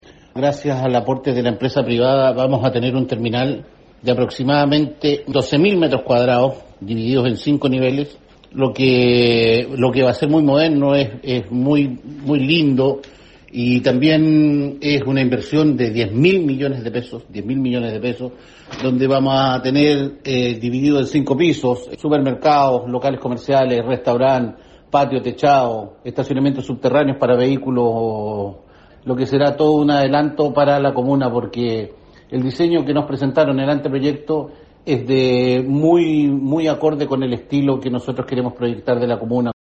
El concejal Jorge Bórquez, se refirió al proceso que permitirá contar con una moderna infraestructura.